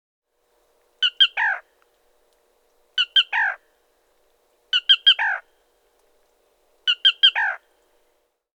Do you know this bird?